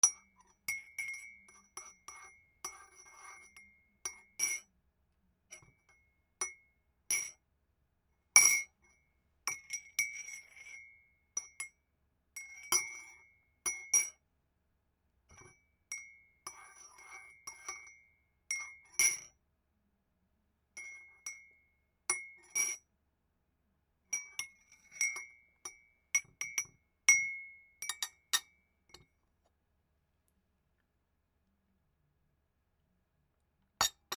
ティースプーン かき回すなど